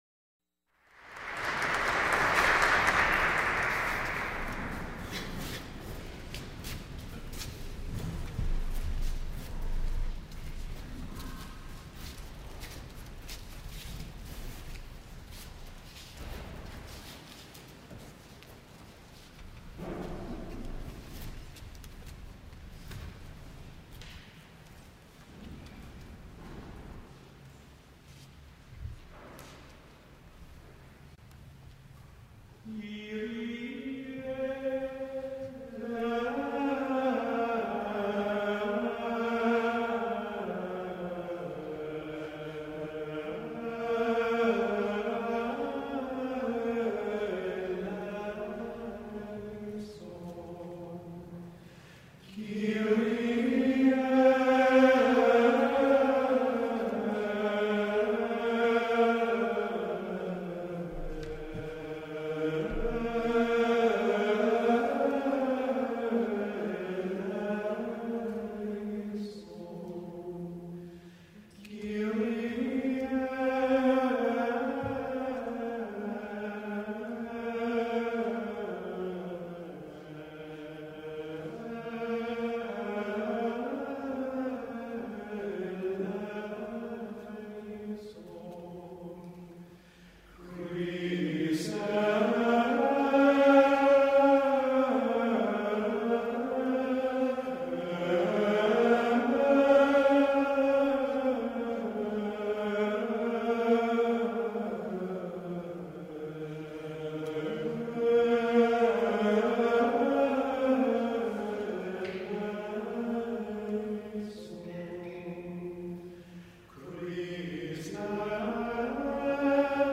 The 1978 Harvard Festival of Men’s Choruses, April 14 & 15, 1978, Saint Paul Church, Pt. 1 & 2 — Harvard Glee Club Alumni